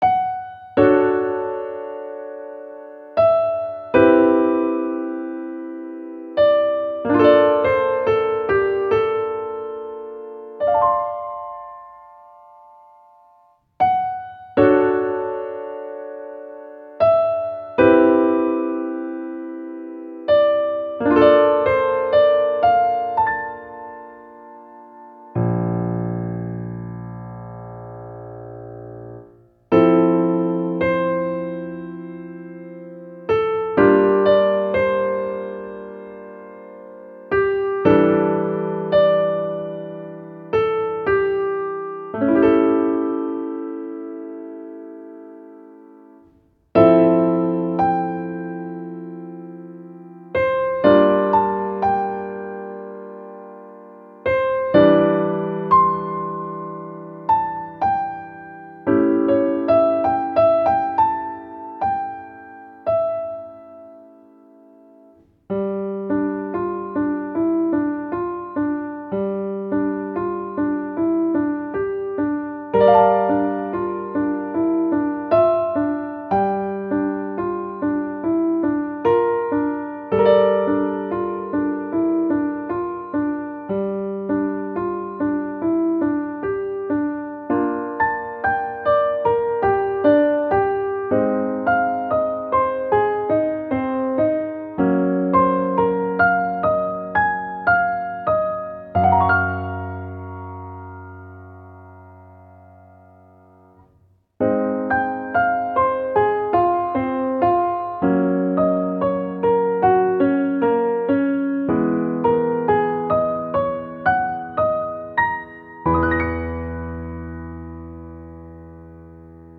-oggをループ化-   しっとり バラード 2:38 mp3